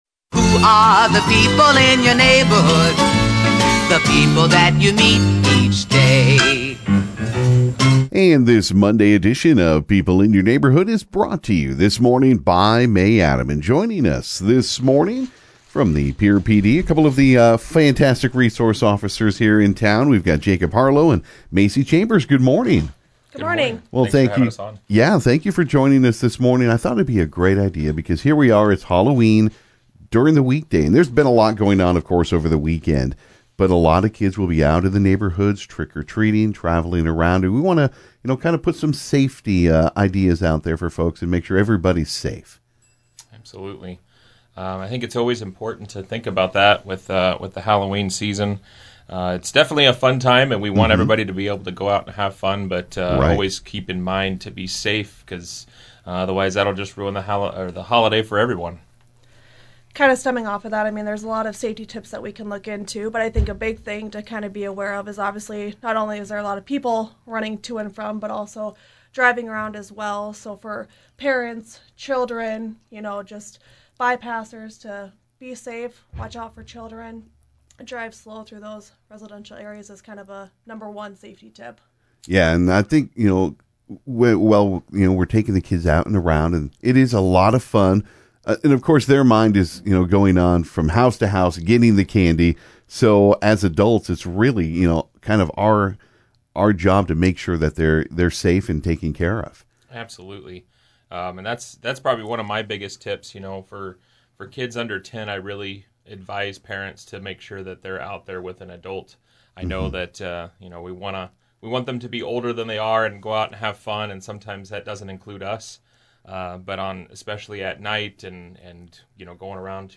stopped by the KGFX studios